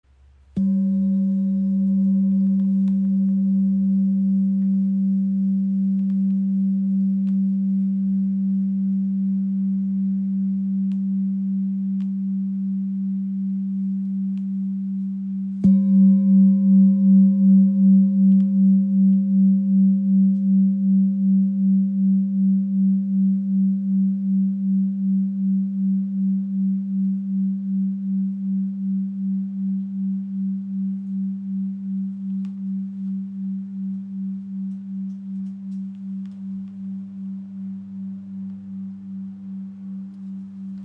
Klangschale Lebensblume | ø 19.8 cm | Ton ~ G | Erdrotation / Tageston (194,18 Hz)
Handgefertigte Klangschale aus Kathmandu
• Icon Inklusive passendem rotem Filzschlägel
Die Erd-Klangschale mit 194,18 Hz trägt die sanfte Schwingung des Tagestons der Erde in sich.
Seine sanfte Schwingung erdet, entspannt und stärkt das Gefühl von Stabilität und innerer Ruhe.